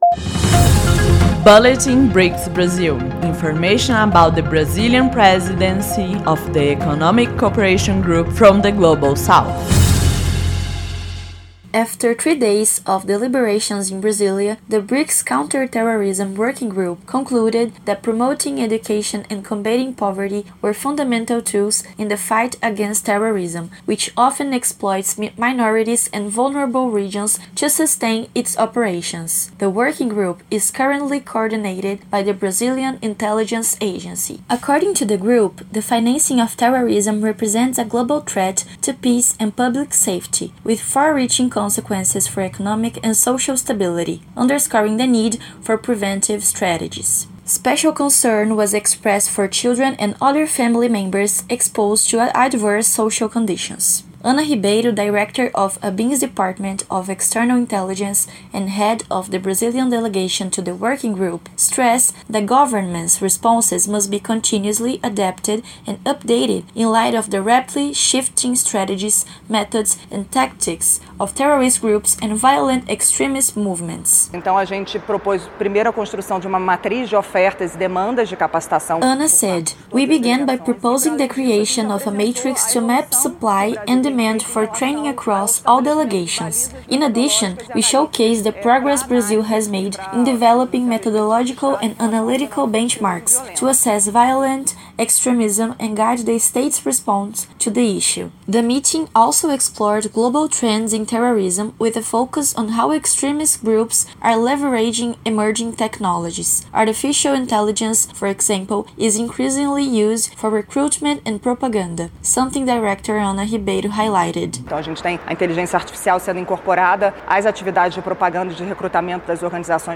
BRICS describes education and the fight against poverty as essential tools to combat terrorism. ABIN led the BRICS Counterterrorism Working Group’s discussions on technology, funding and deradicalization, reinforcing the importance of international cooperation. Listen to the full report here.